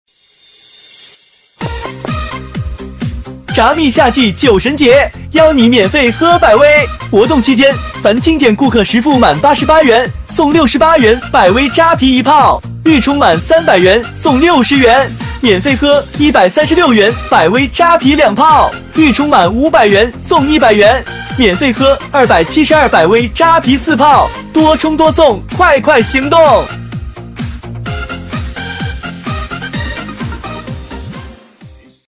【年轻动感男】